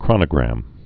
(krŏnə-grăm, krōnə-)